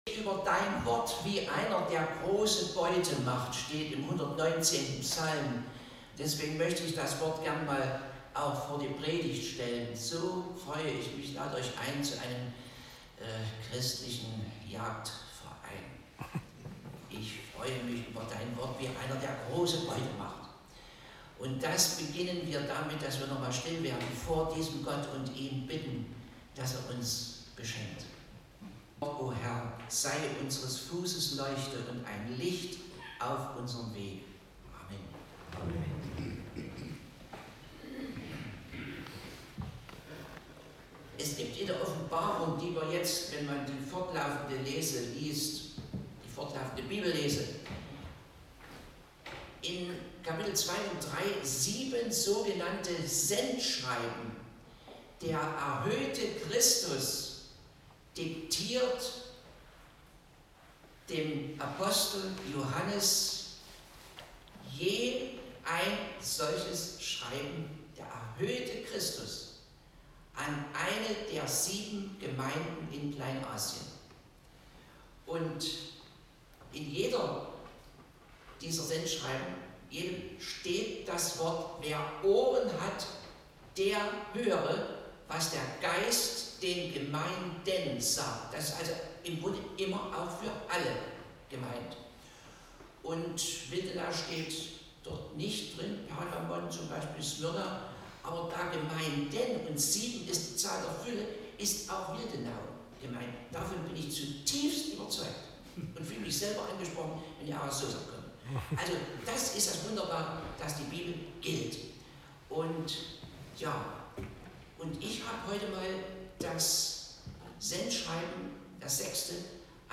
Gottesdienstart: Gemeinsamer Gottesdienst